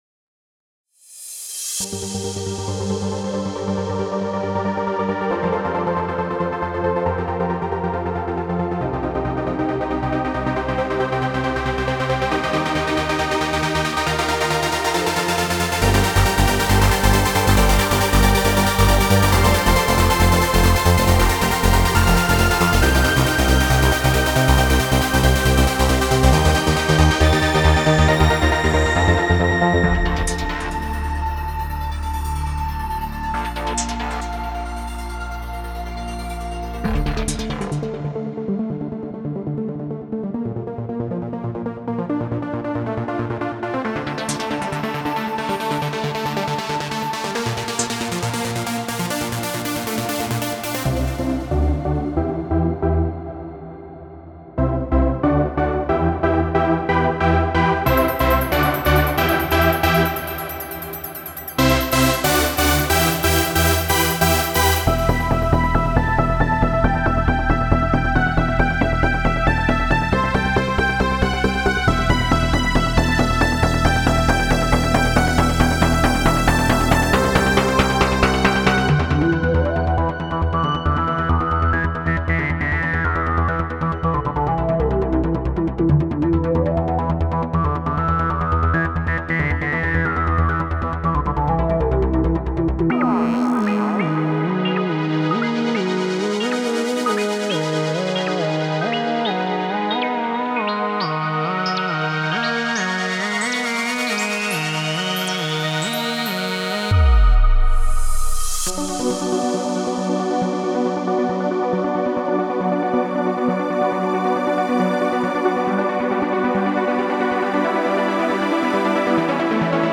trance presets for uhe diva synthesizer
MP3 DEMO